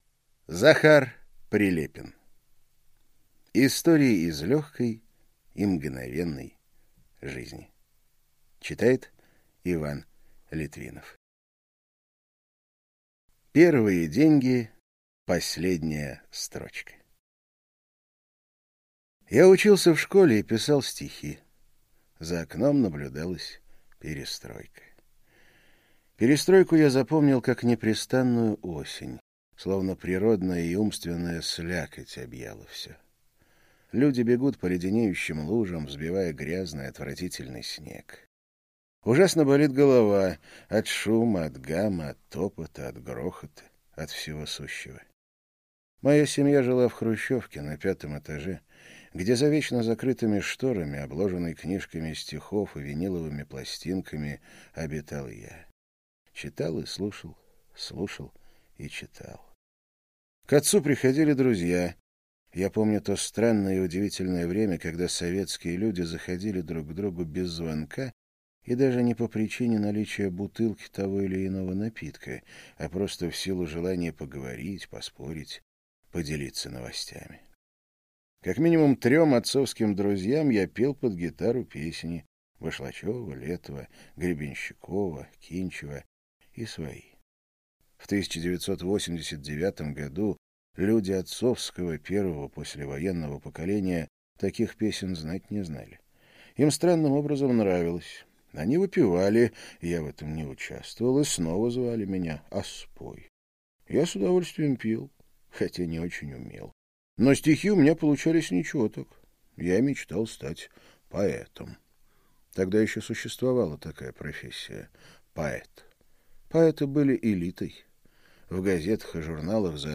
Аудиокнига Истории из лёгкой и мгновенной жизни - купить, скачать и слушать онлайн | КнигоПоиск